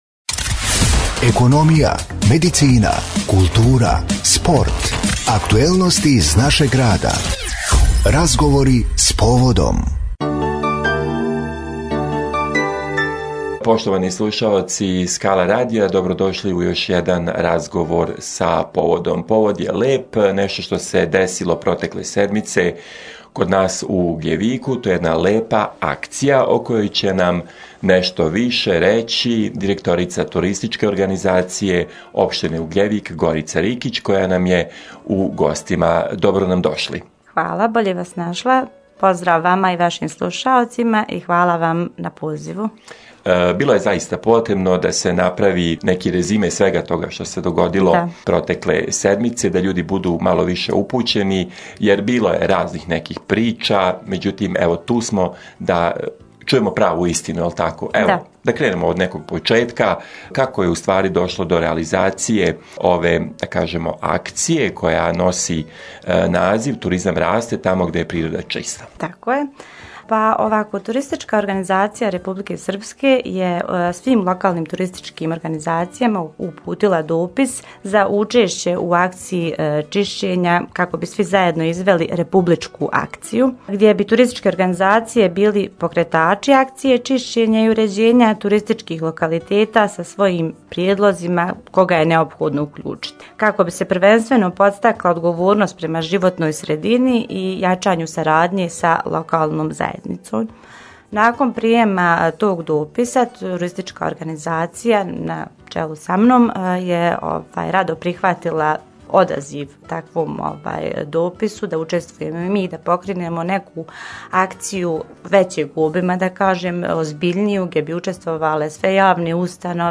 GOST U STUDIJU SKALA RADIJA